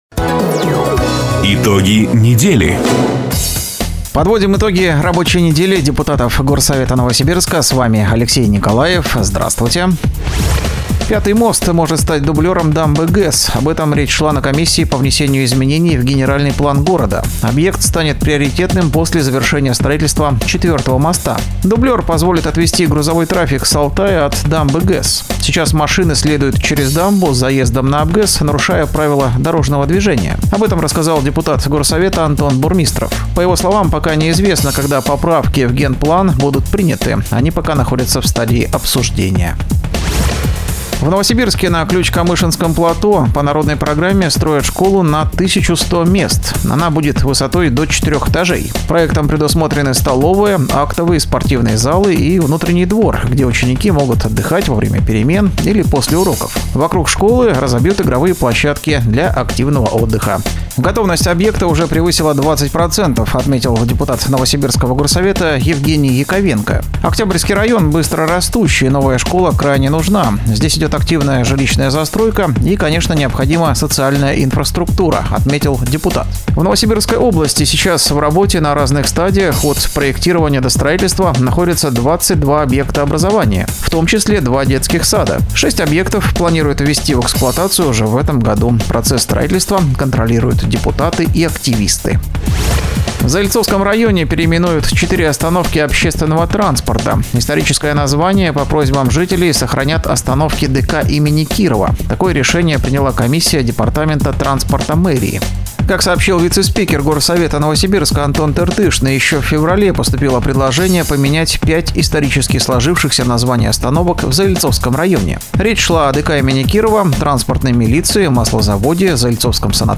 Запись программы "Итоги недели", транслированной радио "Дача" 18 мая 2024 года.